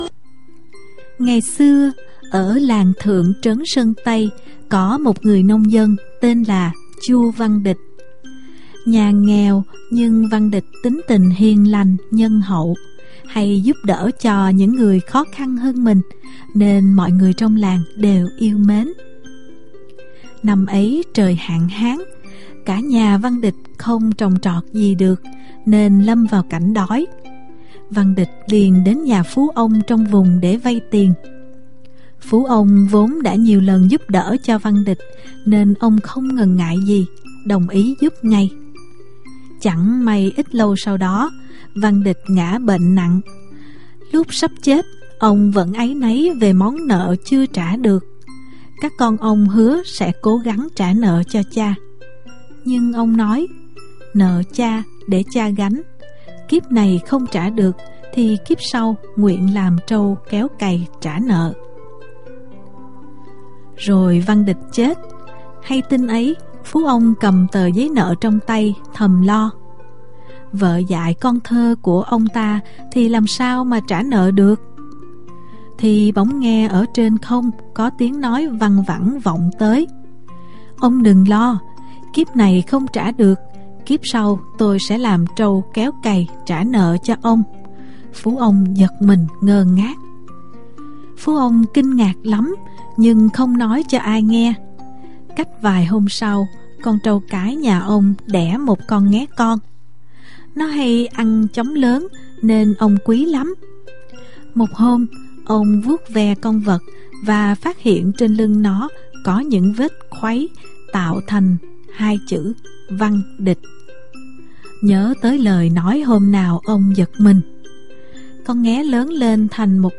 Sách nói | Kéo cày trả nợ